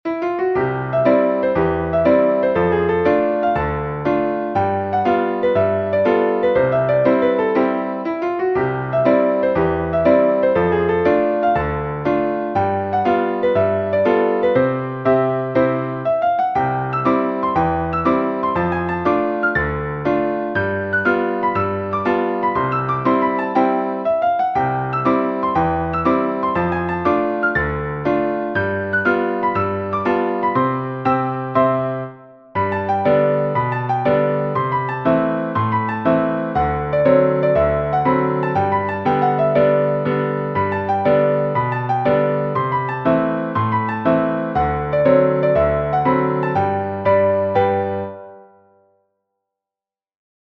Instrument; piano